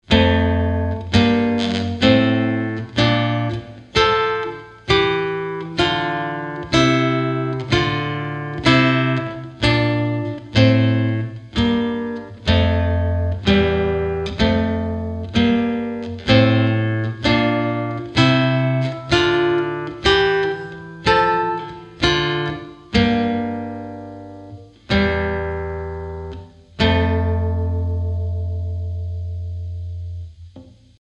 Chitarra sola 52